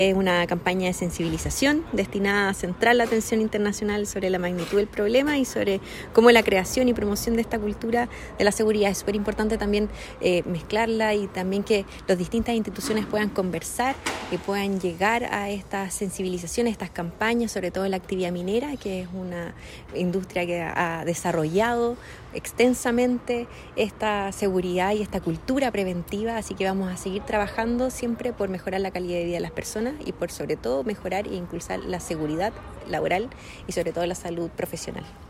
Por su parte, la Seremi de Minería, Constanza Espinosa, destacó la importancia de la prevención de accidentes laborales y enfermedades profesionales, especialmente en la industria minera y dijo que